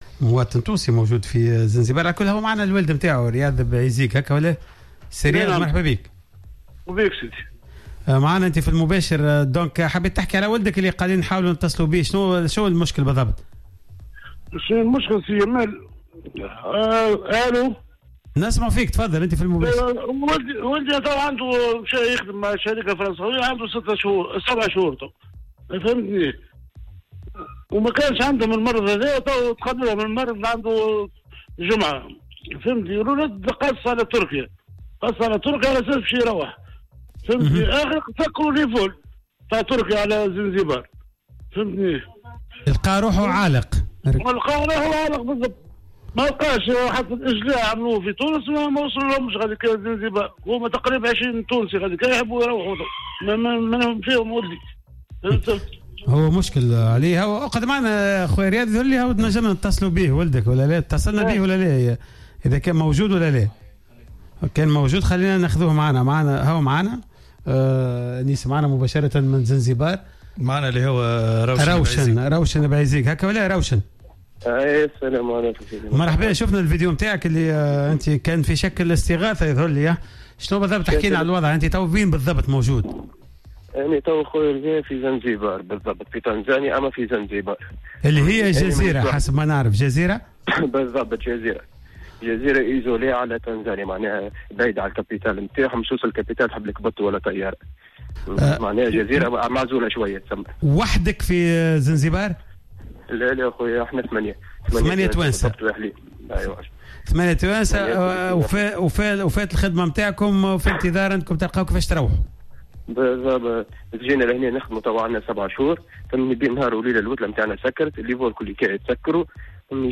تدخل مواطن تونسي في حصة "قوول" اليوم الخميس 09 أفريل 2020 للحديث حول وضعية عدد من التونسيين العالقين في جزيرة زنجبار في تنزانيا بعد تفشي فيروس كورونا.